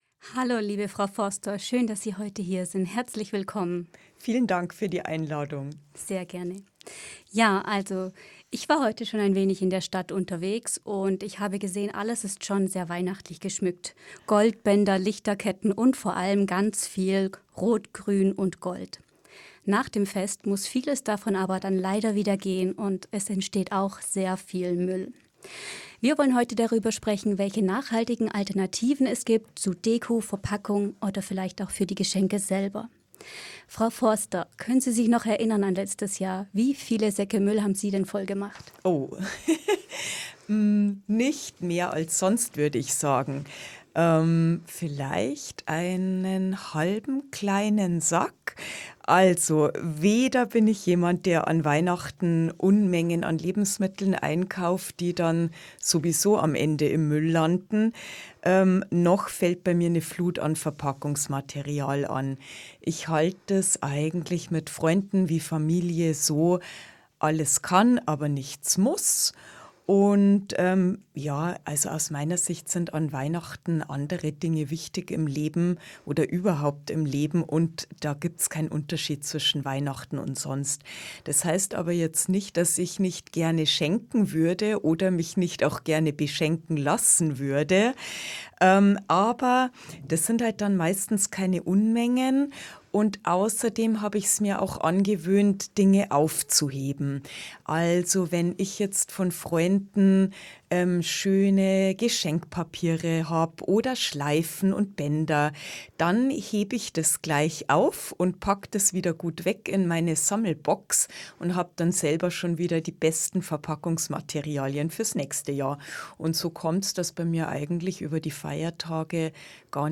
Interview 2